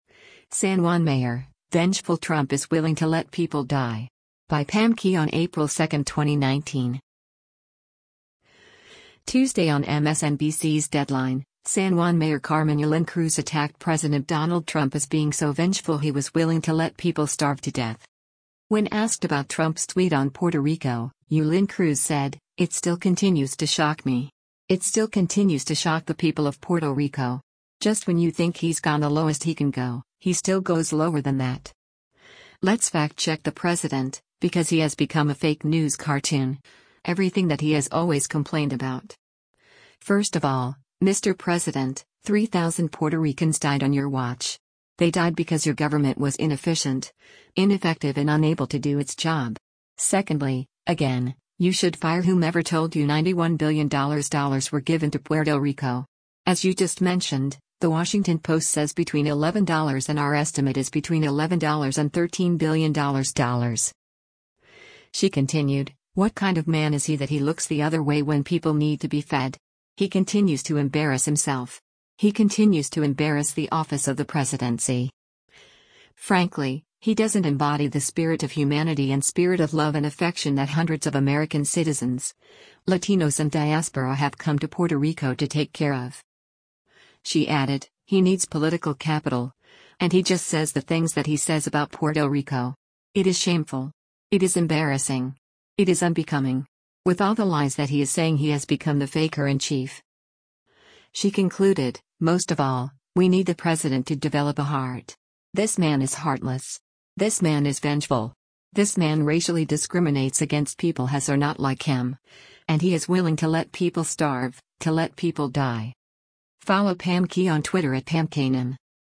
Tuesday on MSNBC’s “Deadline,” San Juan Mayor Carmen Yulin Cruz attacked President Donald Trump as being so “vengeful” he was “willing to let people starve” to death.